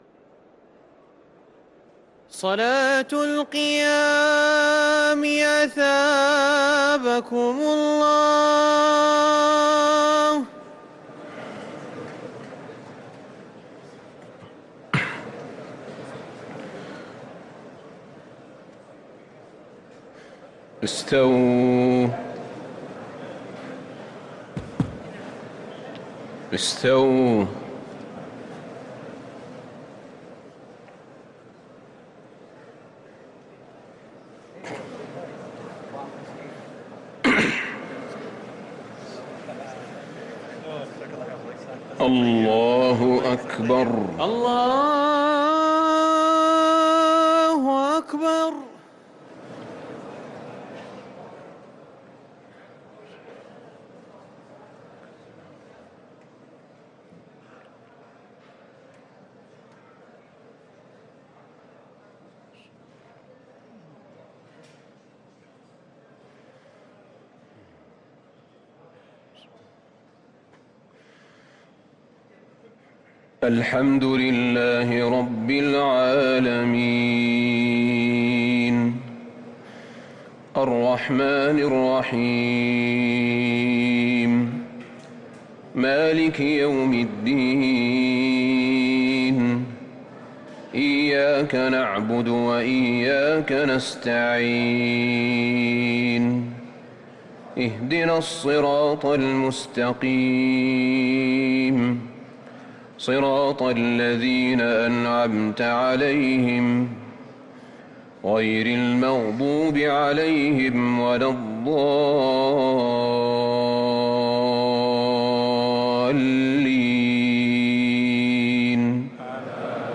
صلاة التراويح ليلة 23 رمضان 1444 للقارئ أحمد بن طالب حميد - الثلاث التسليمات الاولى صلاة التهجد